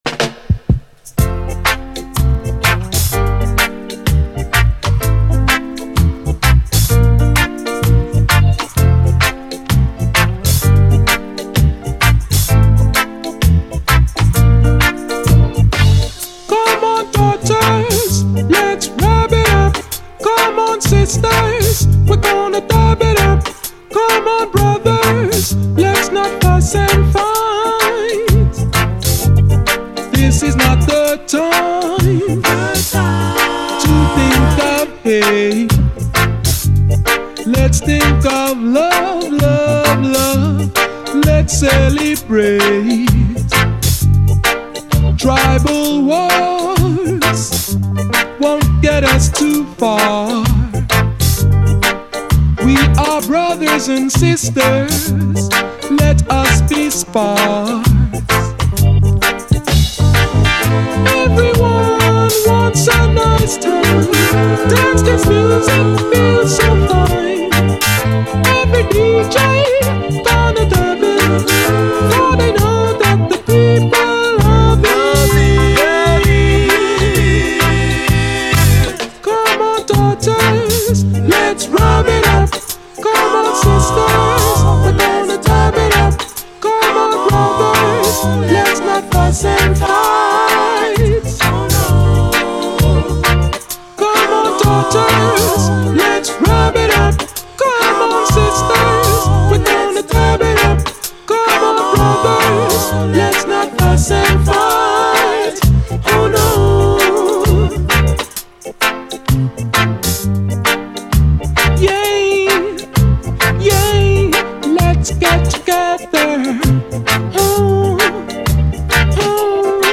REGGAE
UKラヴァーズ・クラシック！
少ない音数でファルセット・ヴォーカルが際立つA面の通常ヴァージョン